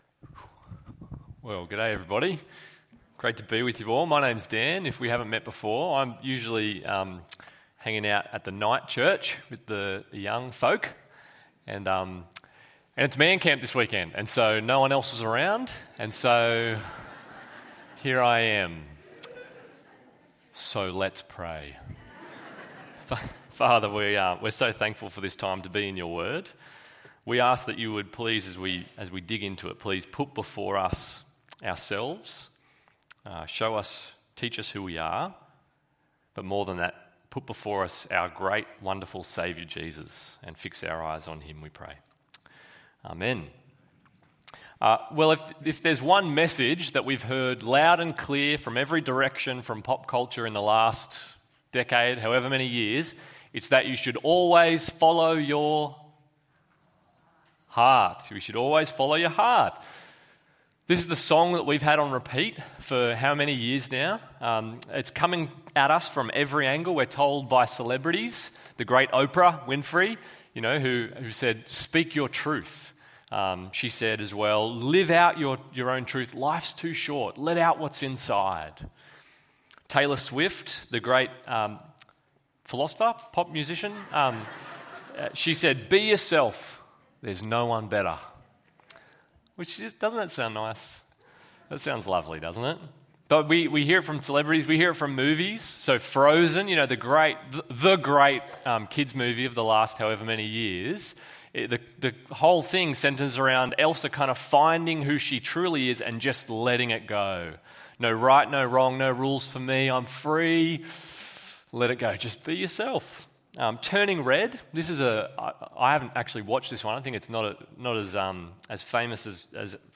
Jesus Denied and Condemned ~ EV Church Sermons Podcast